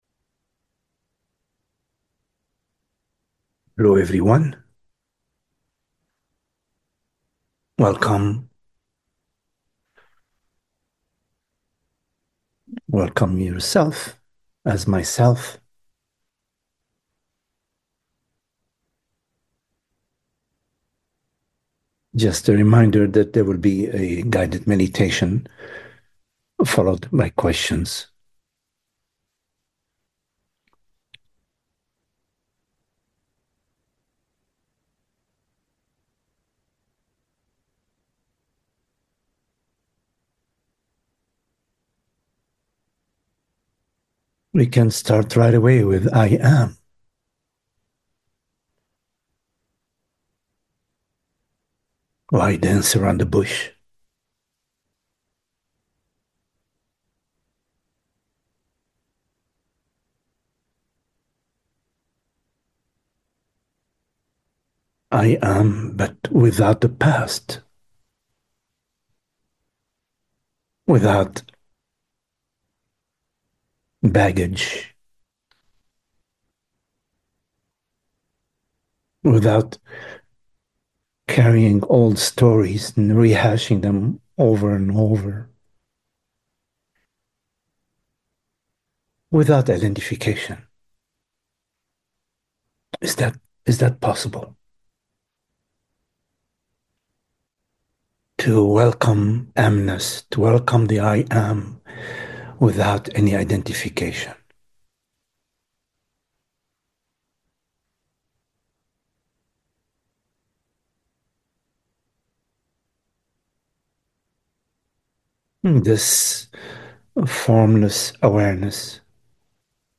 Just a reminder that there will be a guided meditation followed by questions.